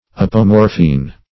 Apomorphia \Ap`o*mor"phi*a\, Apomorphine \Ap`o*mor"phine\, n.